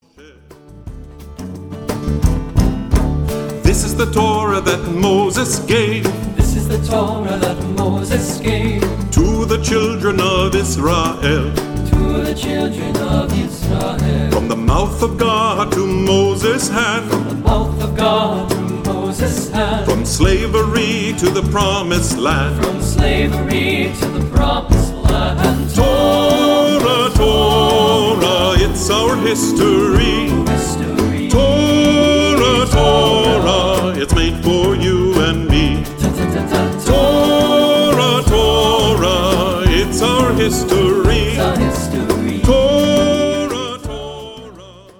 rockin' and rollin'